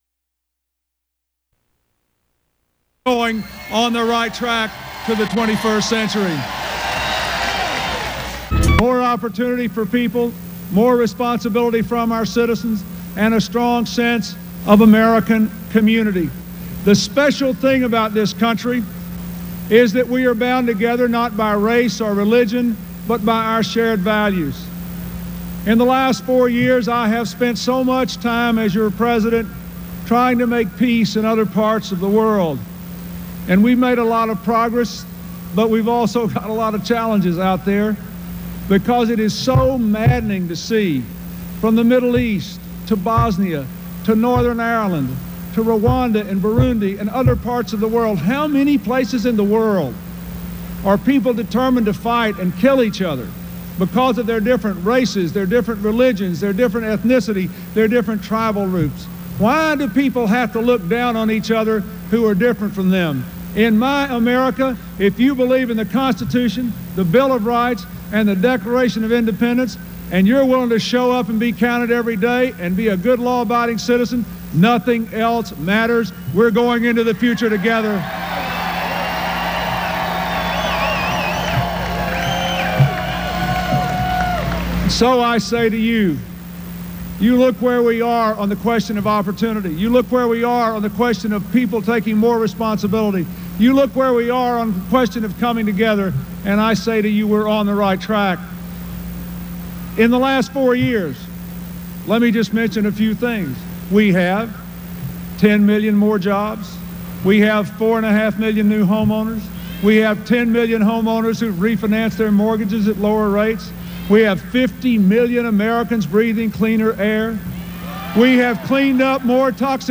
U.S. President Bill Clinton campaigns to 20,000 people on the campus of Michigan State University
Subjects Politics and government Presidents--Election United States Material Type Sound recordings Language English Extent 00:15:45 Venue Note WILX-TV News Special, Aug. 27, 1996.